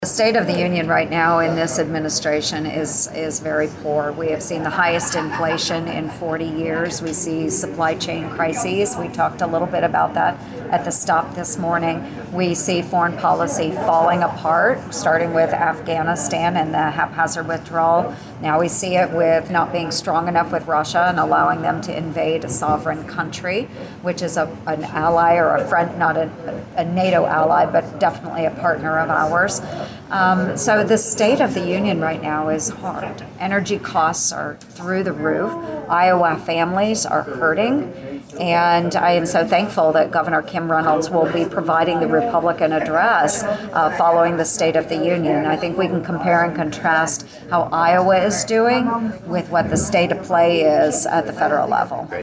Humboldt, IA – Iowa Senator Joni Ernst visited Humboldt last week visiting the Sisters Homestyle Entrées food packaging plant, and the Senator talked to reporters about a wide range of topics, Ernst was asked about her thoughts of the State of the Union as President Biden sets to address the Nation on Tuesday.